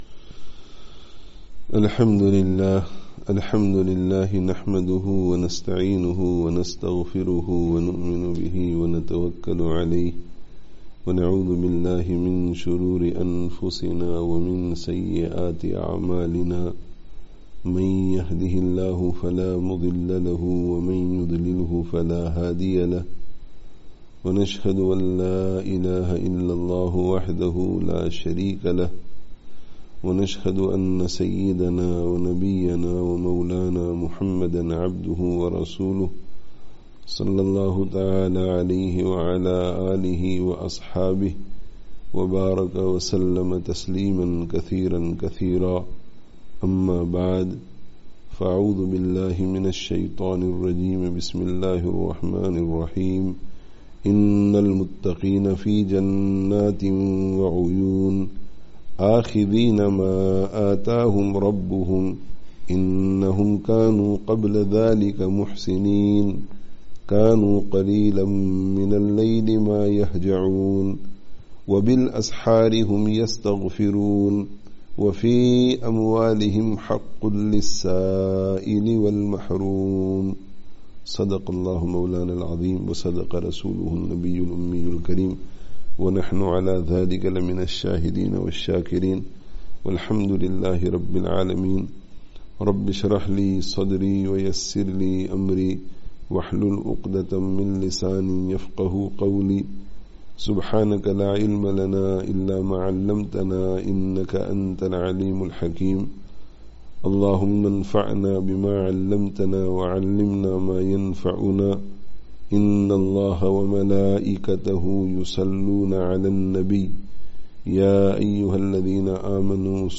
Dars of Qur'an